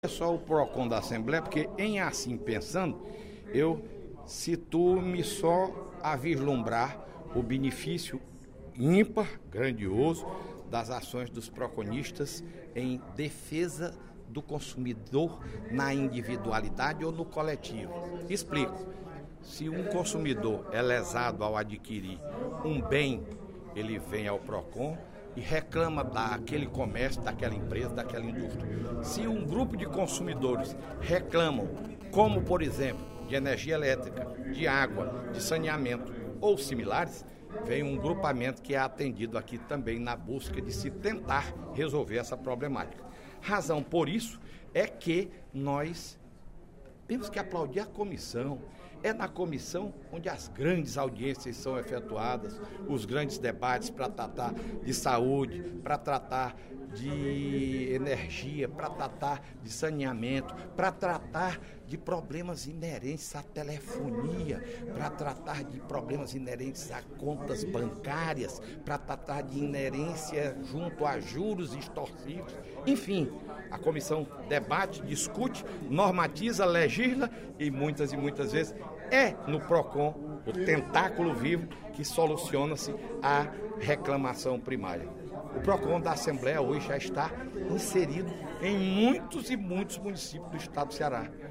O deputado Fernando Hugo (PP) ressaltou, no primeiro expediente da sessão plenária desta quarta-feira (15/03), o Dia Mundial dos Direitos do Consumidor, celebrado hoje.